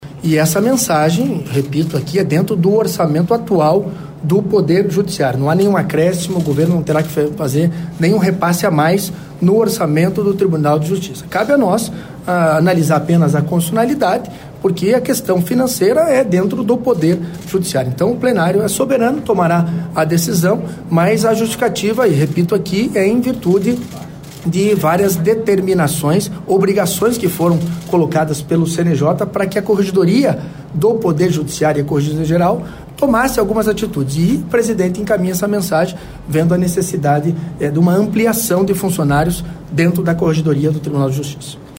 O impacto financeiro estimado com a criação dos cargos é de R$ 2,8 milhões, segundo o projeto. O presidente da Casa afirmou que está dentro do teto de responsabilidade fiscal do Judiciário.